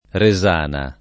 [ re @# na ]